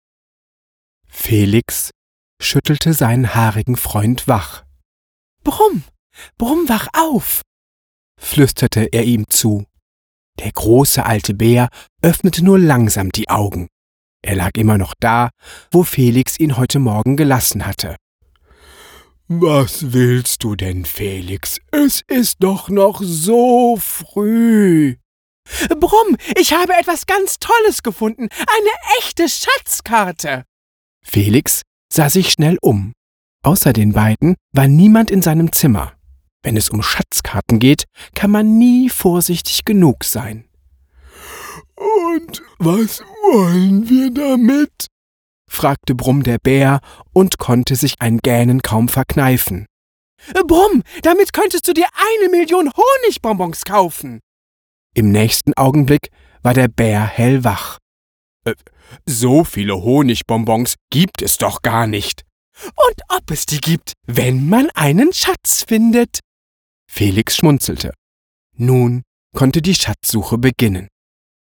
Deutscher Sprecher, helle Stimme, Werbesprecher, Schauspieler, Synchronsprecher
Sprechprobe: Sonstiges (Muttersprache):
Brumm und Felix - Kindergenre - drei Stimmen.mp3